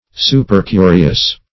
Search Result for " supercurious" : The Collaborative International Dictionary of English v.0.48: Supercurious \Su`per*cu"ri*ous\, a. Excessively curious or inquisitive.